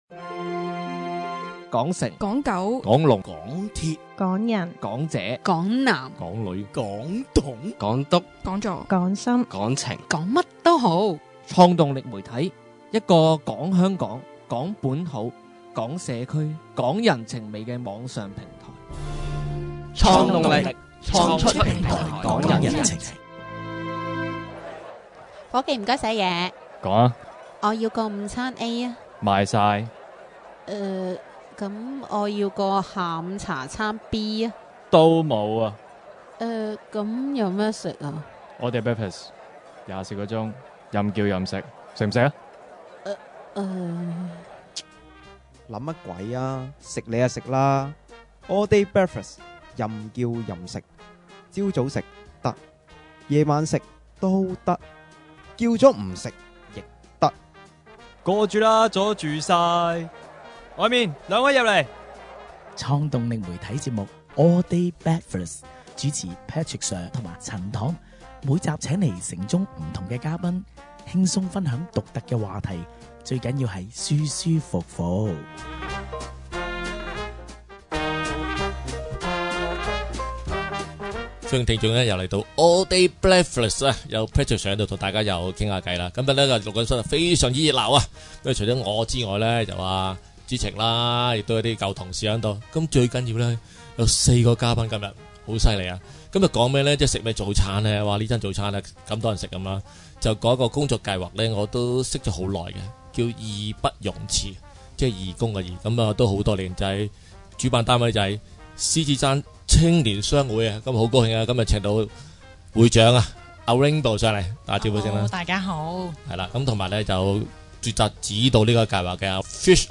獅子山青年商會與東華三院合辦義不容遲工作計劃，今年新嘗試據辦全城護眼日!希望喚起都市人正視眼部及視力健康, 期望長遠減少眼疾問題, 推動健康城市的概念。今集訪問兩位護眼推廣大使, 他們都是視障人士，